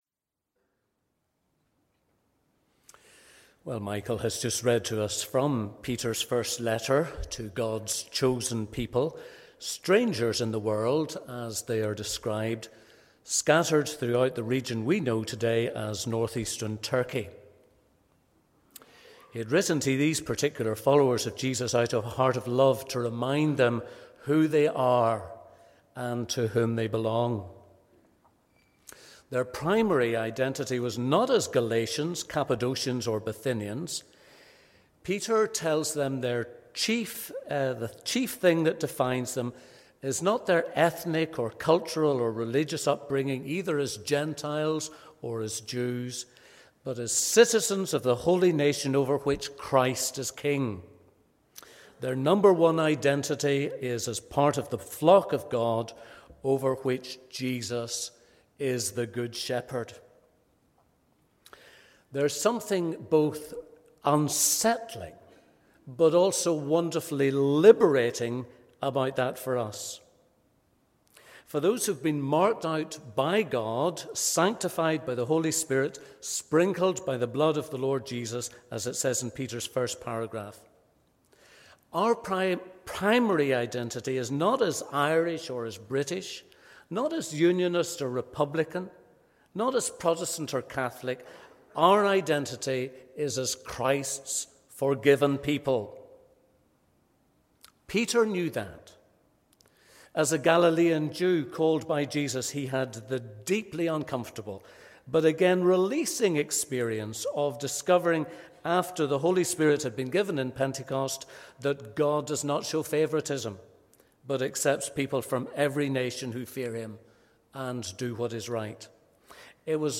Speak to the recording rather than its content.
The Assembly met in Assembly Buildings, Belfast from Monday, 2nd June until Thursday, 5th June, 2014.